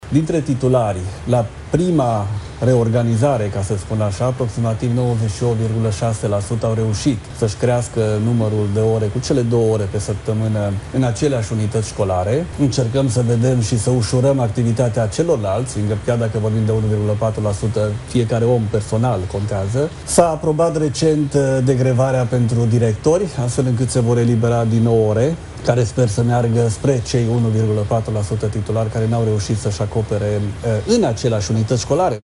Ministrul Daniel David, într-un interviu la Prima News: „Încercăm să ușurăm activitatea celorlalți, chiar dacă vorbim de 1,4%, fiecare om contează”